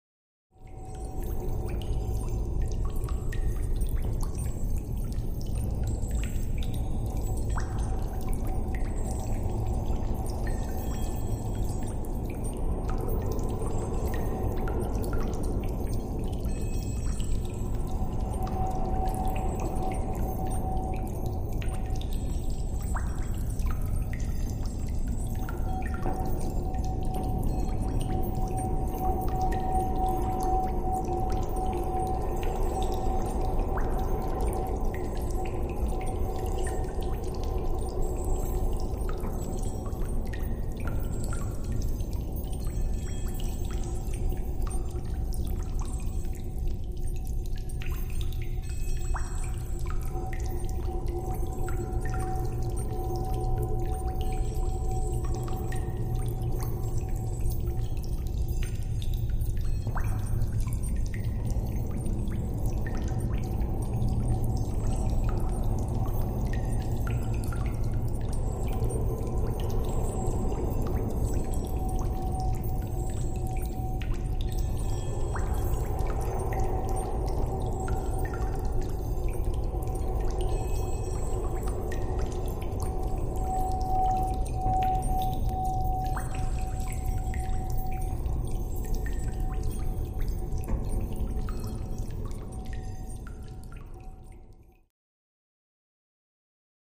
Dungeon Ambiance